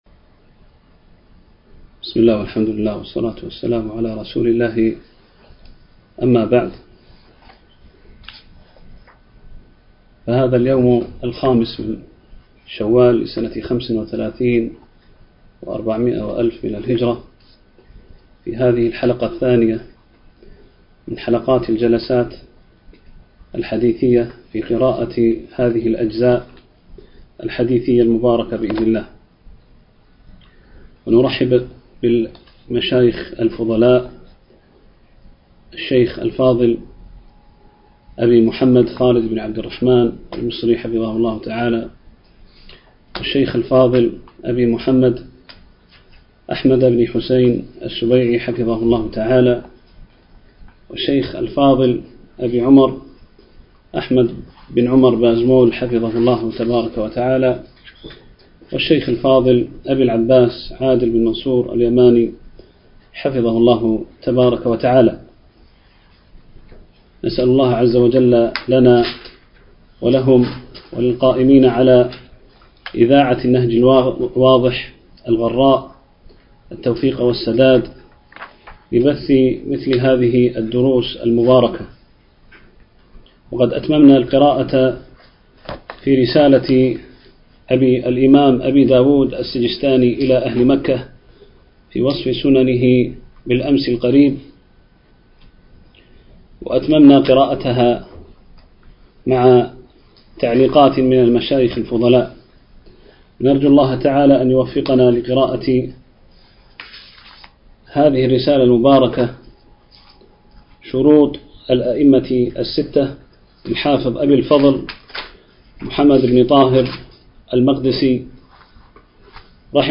الدروس المنقولة عبر إذاعة النهج الواضح
MP3 Mono 22kHz 32Kbps (CBR)